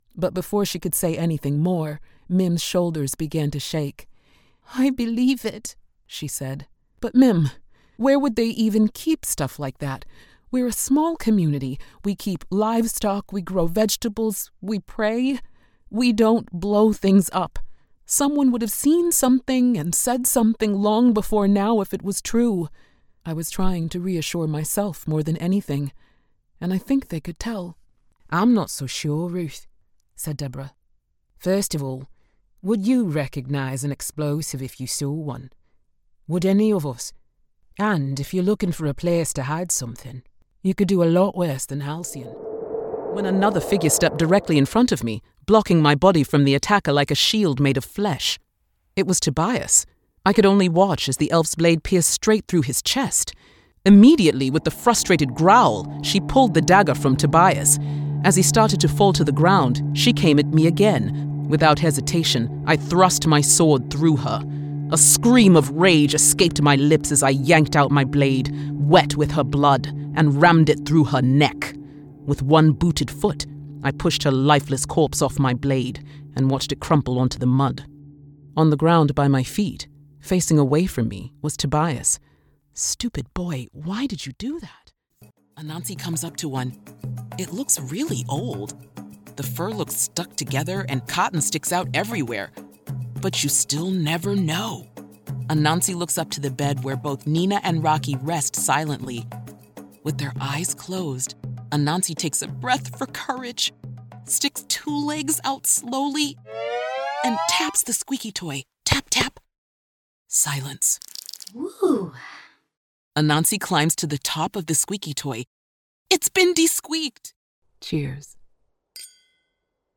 Audiobook Narration
Voice actress with warm, rich tones conveying strength and wisdom.